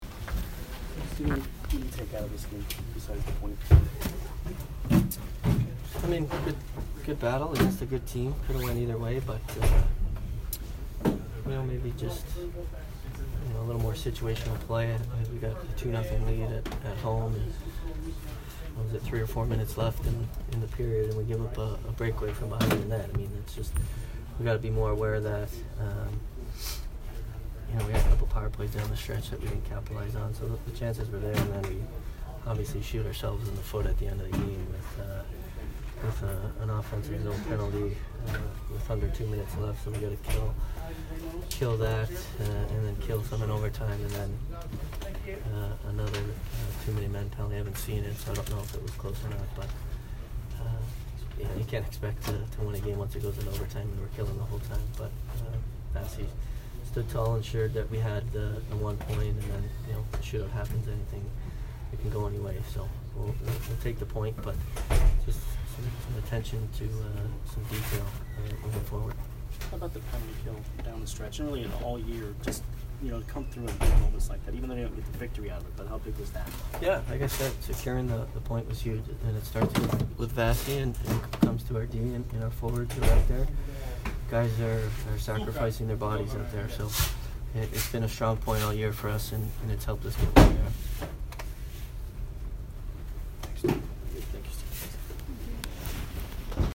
Steven Stamkos post-game 2/5